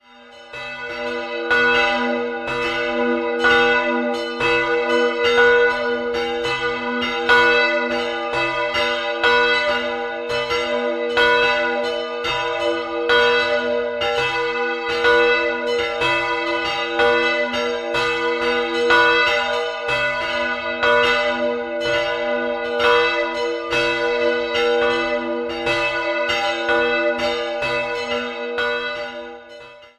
Ansonsten wurde der Bau im Lauf der Jahrhunderte merhfach verändert, zuletzt 1698. 3-stimmiges Geläute: h'-e''-f''' Die kleine Glocke wurde um 1400, die mittlere bereits um 1300 gegossen.